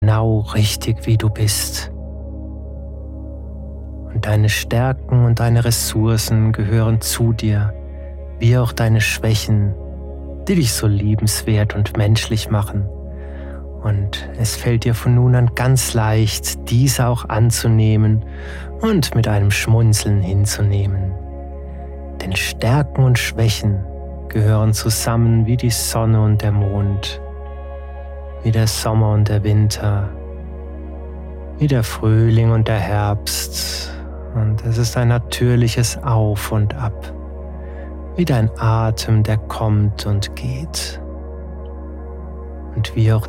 Audio-Hypnosen
mit ausgewählter und stimmungsvoller Musik untermalt